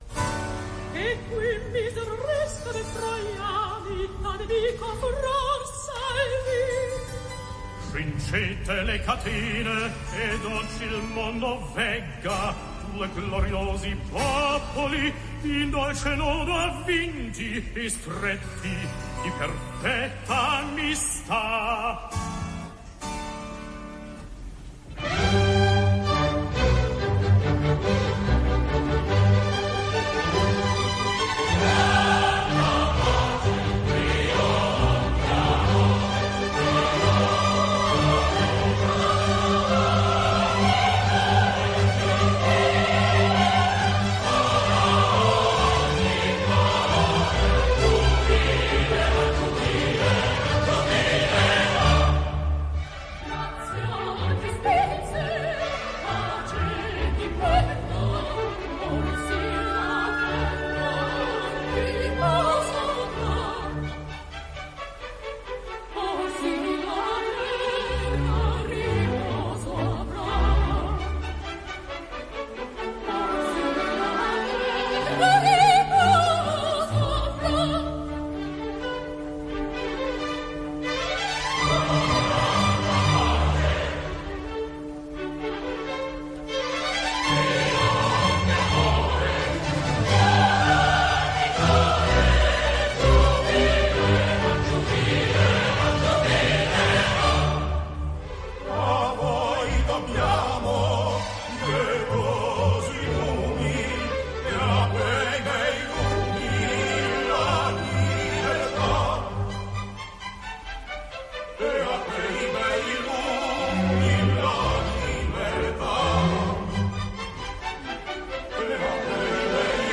registrazione concerto radio.